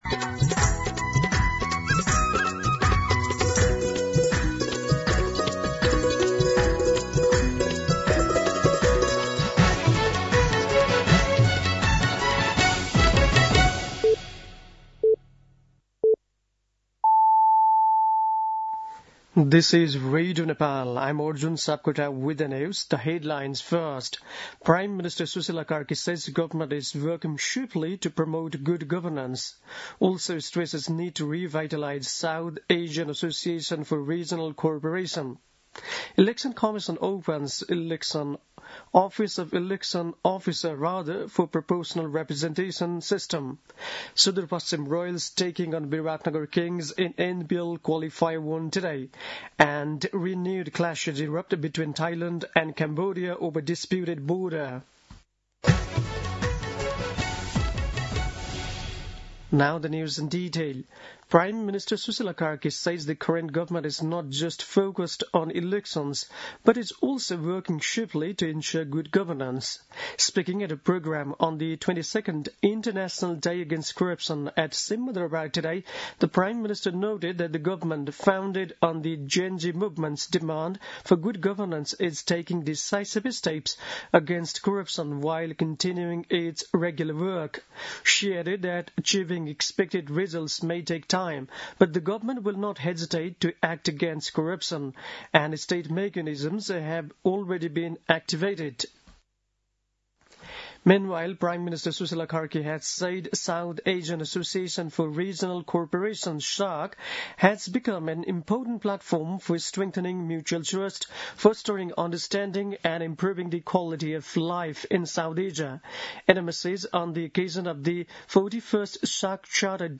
दिउँसो २ बजेको अङ्ग्रेजी समाचार : २३ मंसिर , २०८२
2-pm-English-News-08-23.mp3